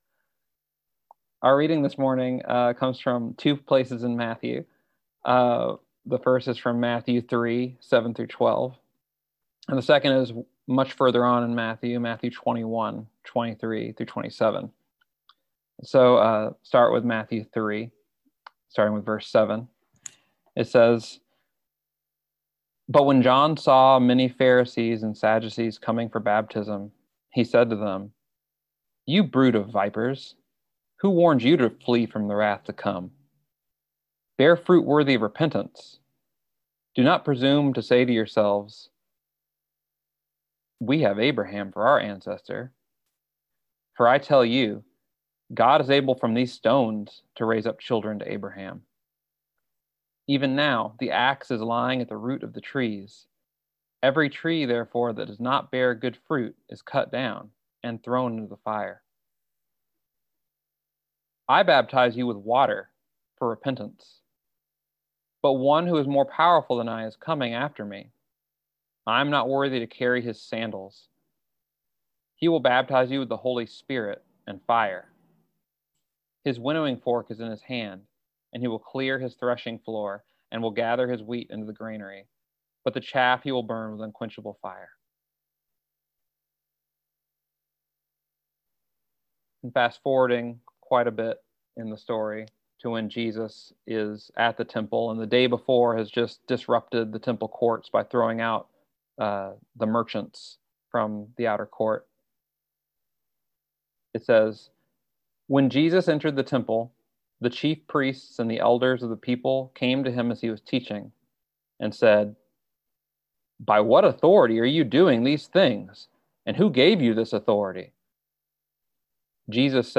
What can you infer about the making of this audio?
Listen to the most recent message from Sunday worship at Berkeley Friends Church, “By What Authority?”